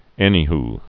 (ĕnē-h)